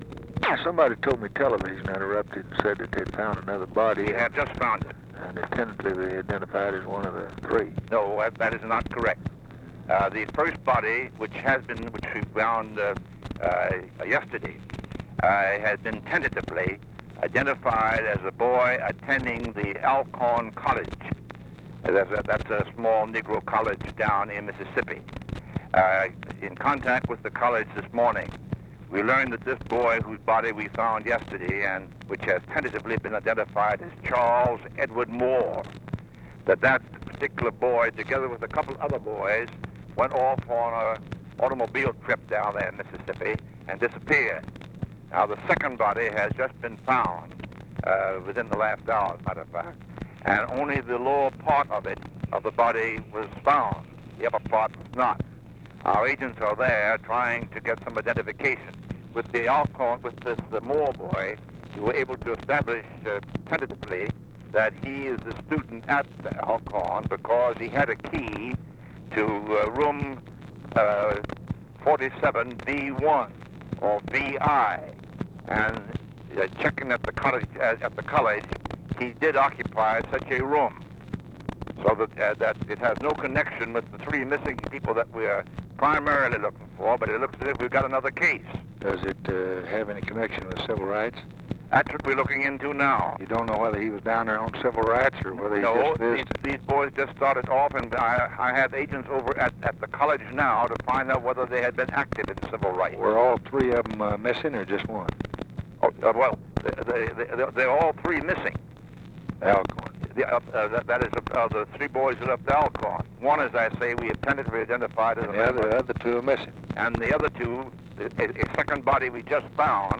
Conversation with J. EDGAR HOOVER, July 13, 1964
Secret White House Tapes